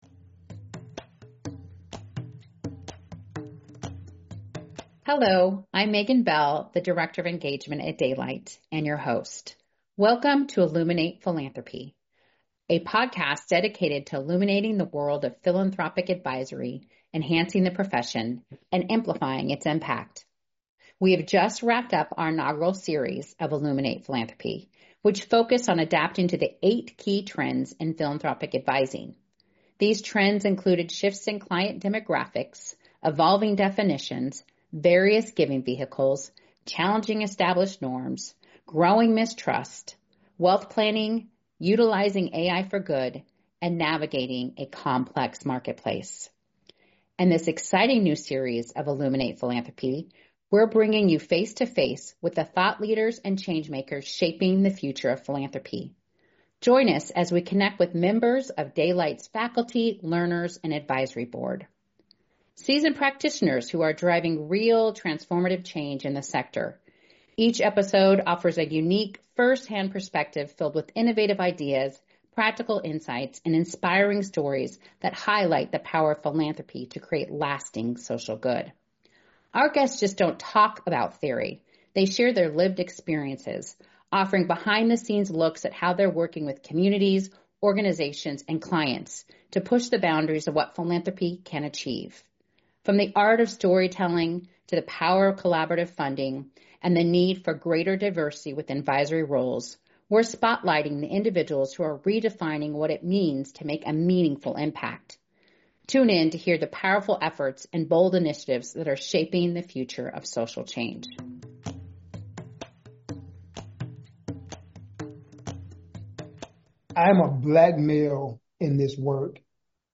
Ep11 Illuminate Philanthropy — An interview